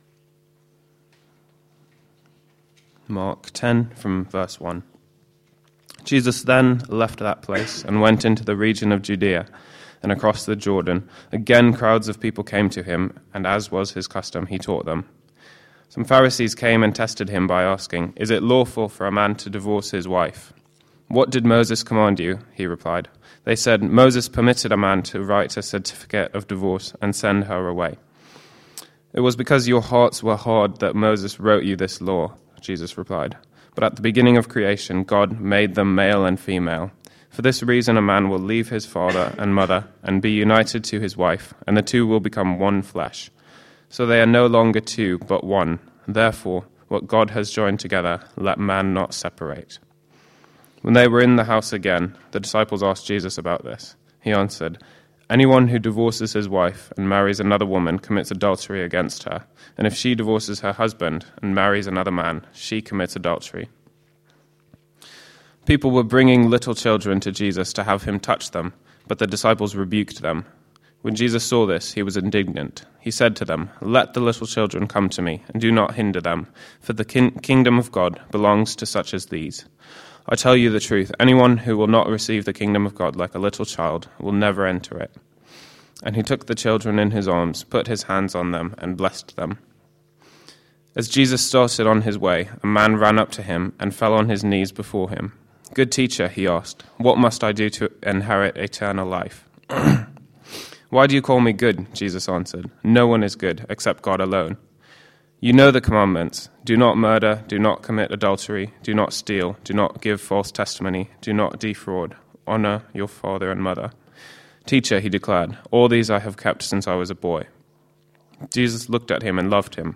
A sermon preached on 19th February, 2012, as part of our Mark series.